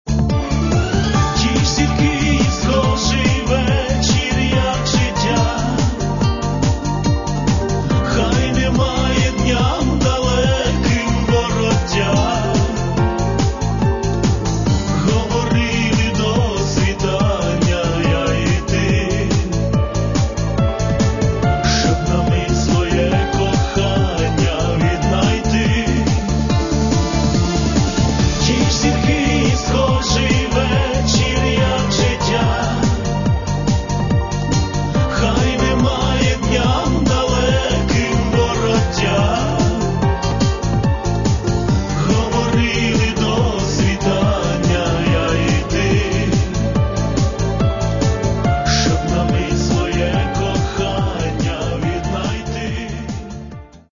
Каталог -> Естрада -> Співаки